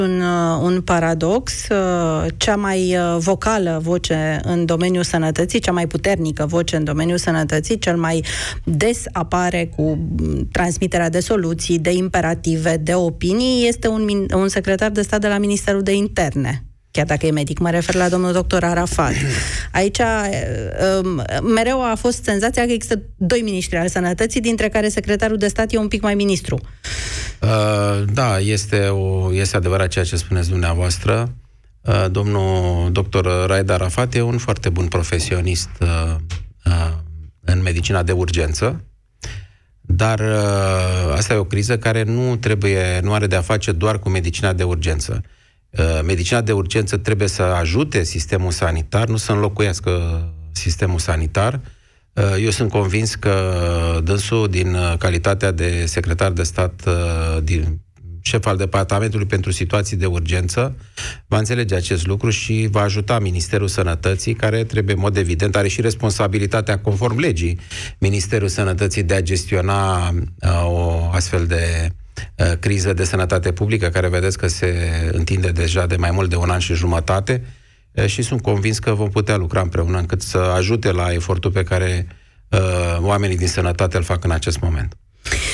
„Este adevărat ceea ce suneți – domnul doctor Raed Arafat este un foarte bun profesionist în medicina de urgență, dar asta este o criză care nu are de-a face doar cu medicina de urgență”, a spus Alexandru Rafila la Europa FM.